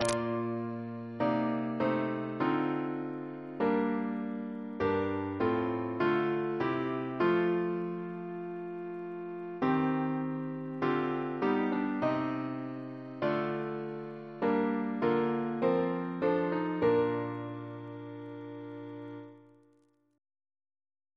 Double chant in B♭ Composer: Sir Ivor Algernon Atkins (1869-1953), Organist of Worcestor Cathedral Reference psalters: ACP: 58